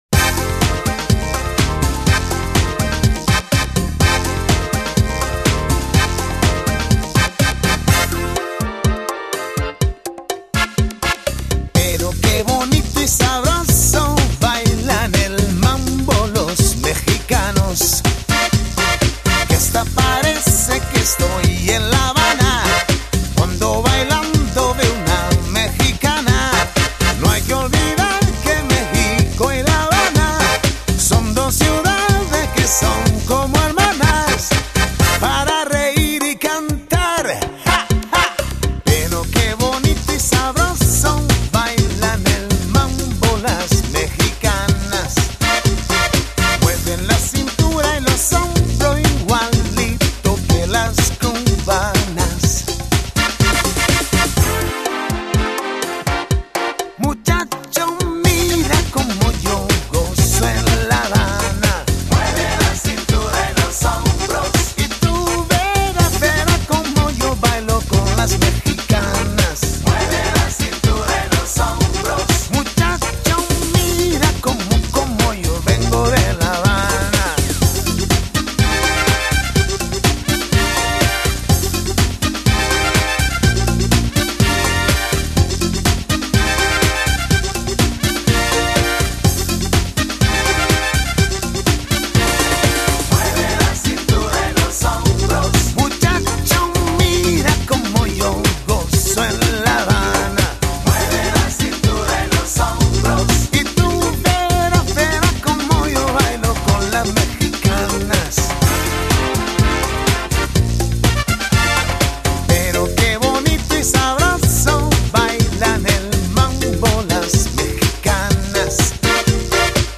03 Cha-Cha-Cha